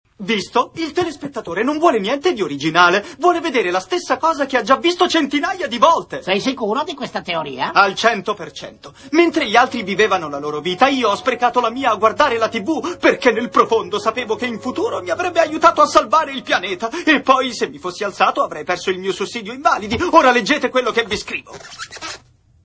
dal cartone animato "Futurama"